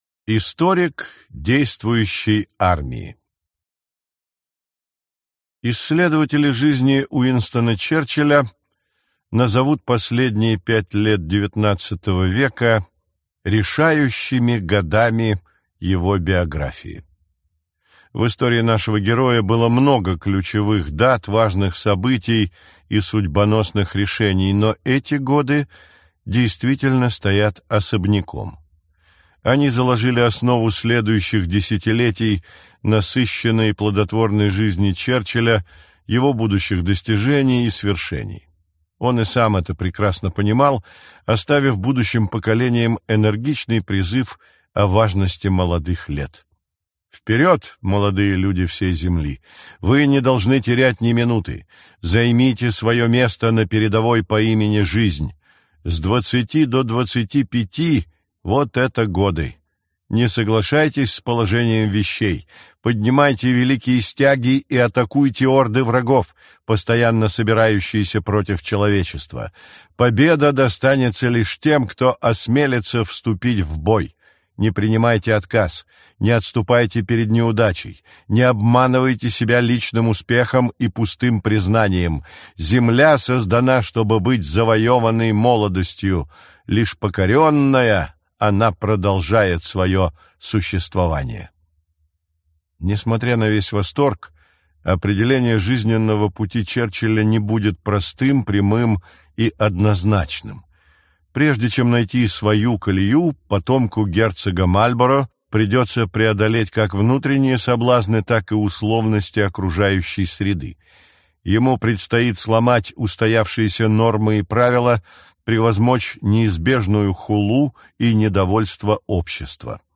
Аудиокнига Черчилль. Биография. Часть 2. Историк действующей армии | Библиотека аудиокниг